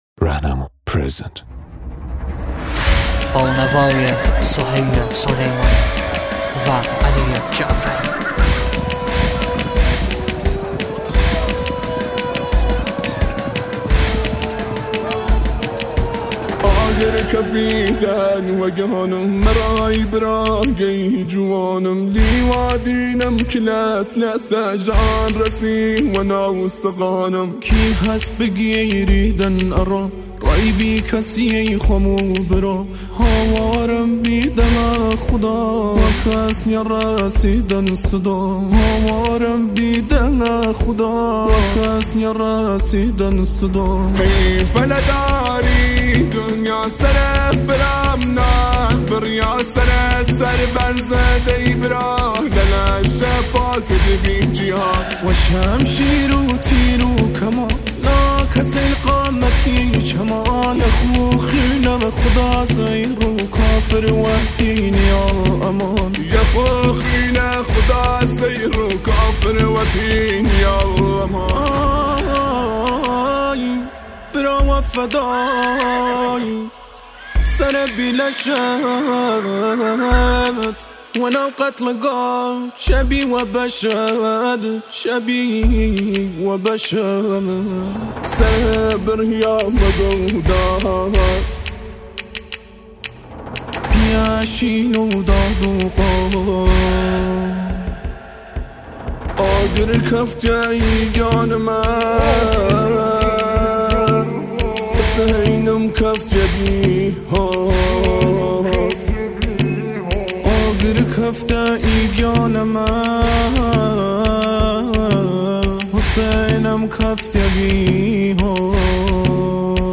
مداحی